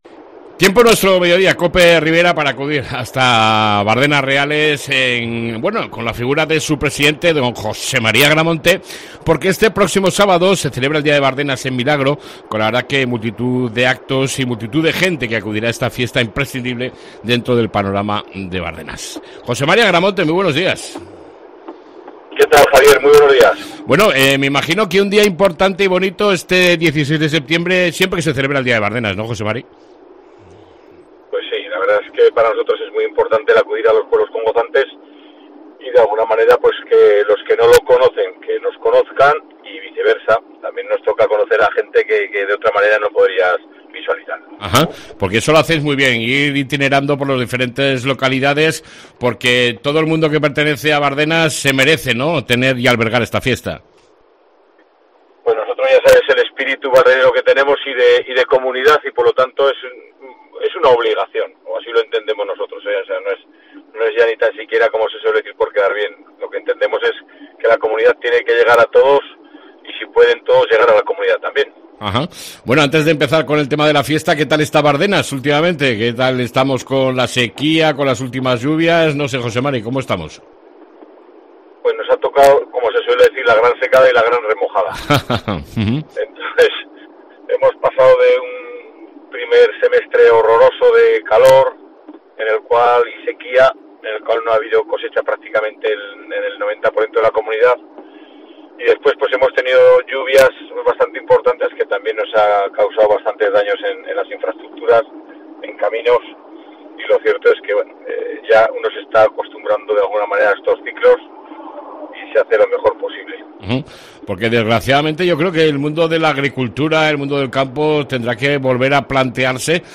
ENTREVISTA CON EL PRESIDENTE DE BARDENAS , JOSE Mª AGRAMONTE